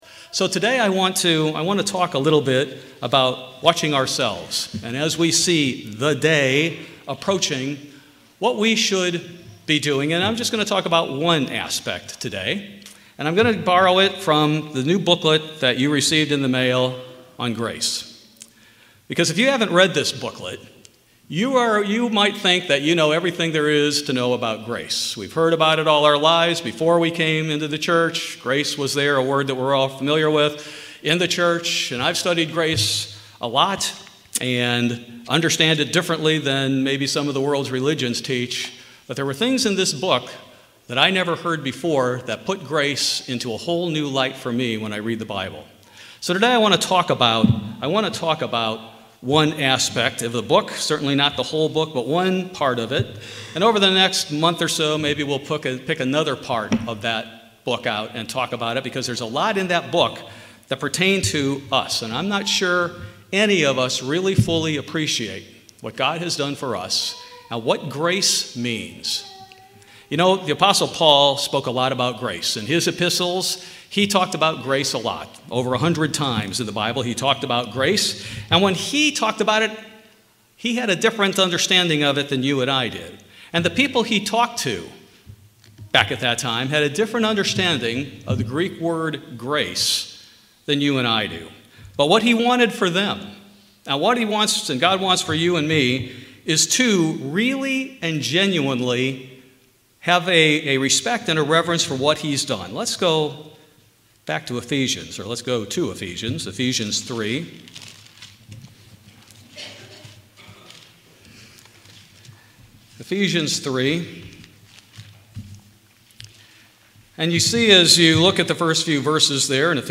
Many people and religions have different ideas about what “grace” means and whether it “requires” anything from the recipient. In this sermon, we will see what the Greek “charis” meant to people in early New Testament times, what grace really encompassed, and how they knew they must respond.